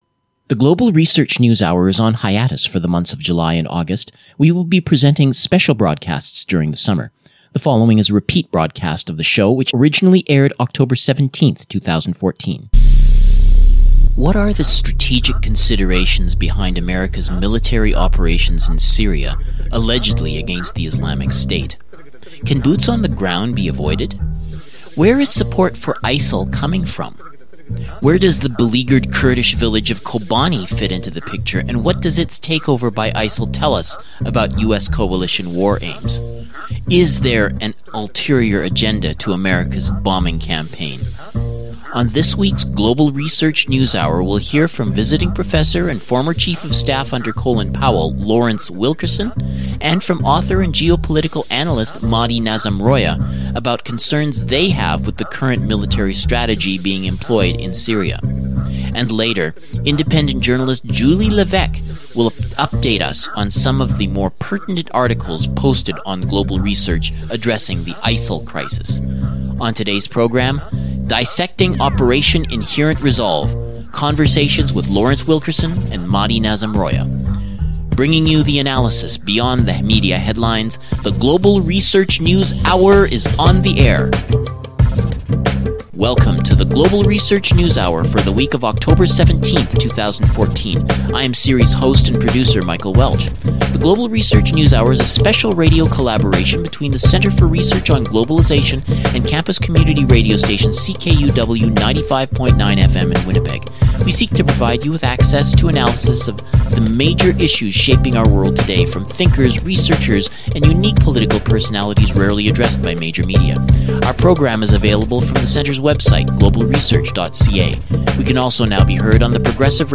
Repeat broadcast from October of 2014